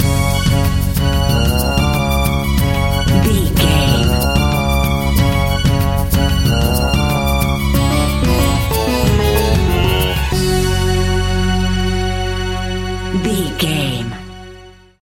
Aeolian/Minor
F#
World Music
percussion